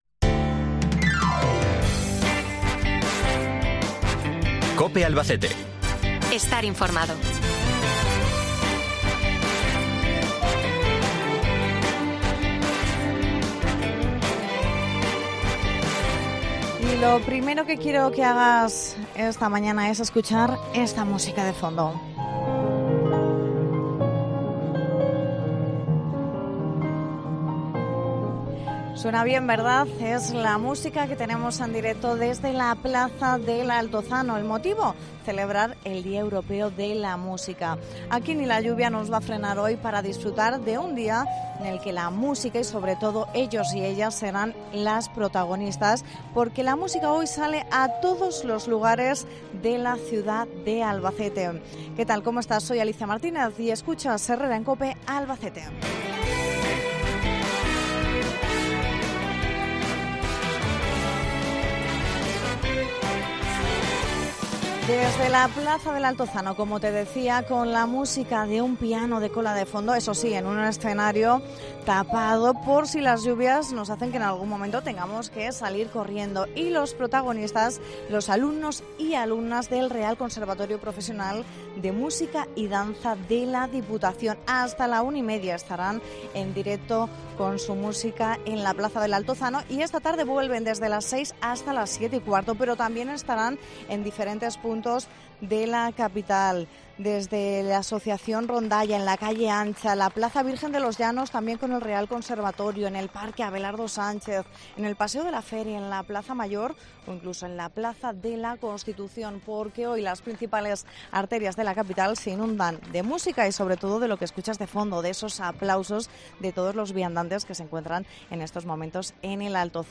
Hoy hablamos del Día Europeo de la Música con sus protagonistas desde la plaza del Altozano